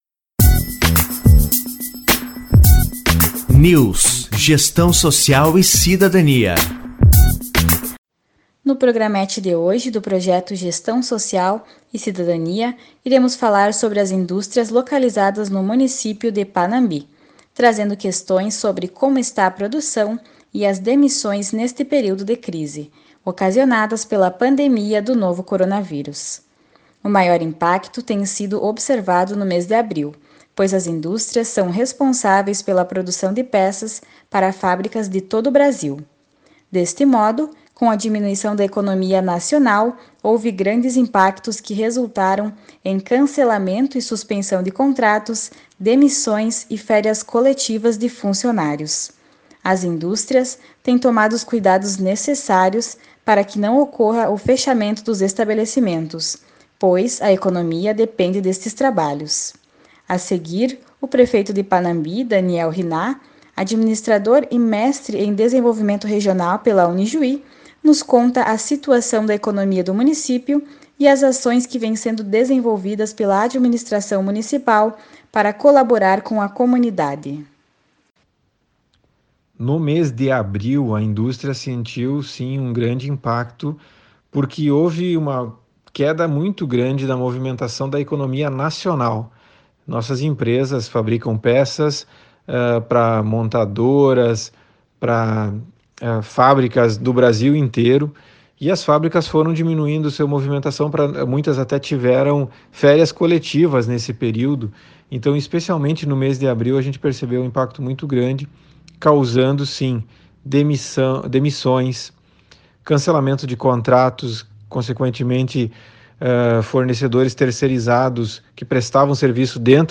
Entrevistado: Administrador, Mestre em Desenvolvimento Regional pela Unijuí e Prefeito de Panambi, Daniel Hinnah.